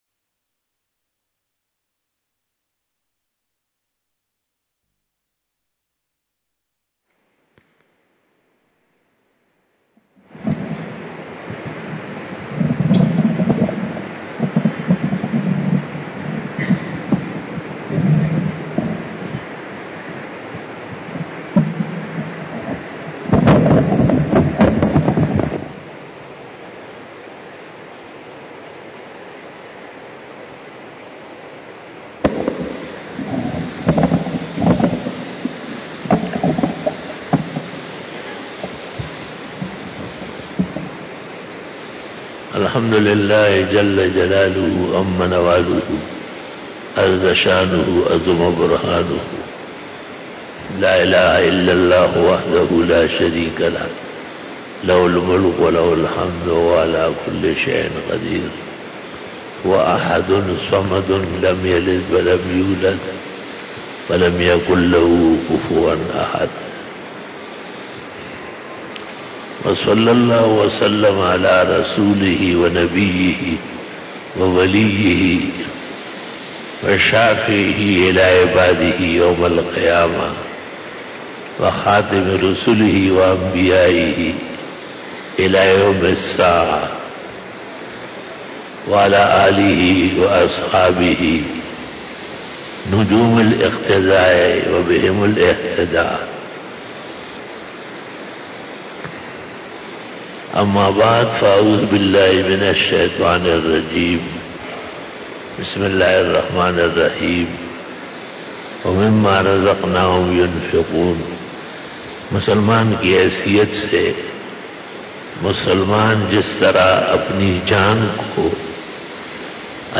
32 BAYAN E JUMA TUL MUBARAK 18 September 2020 (29 Muharram 1442H)
Khitab-e-Jummah